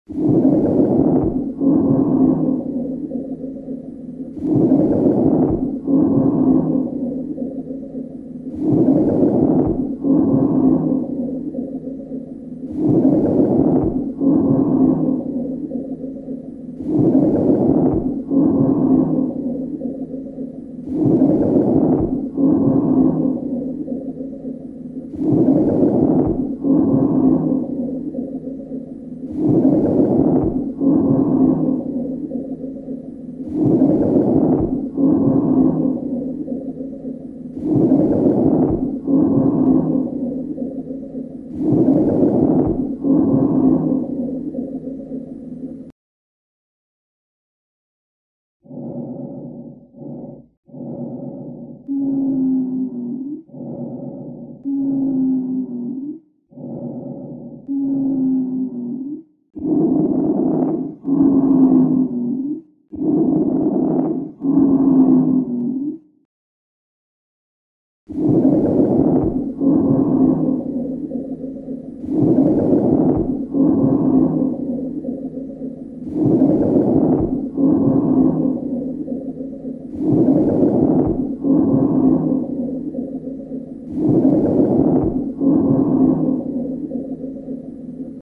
Звук дыхания легких при коронавирусе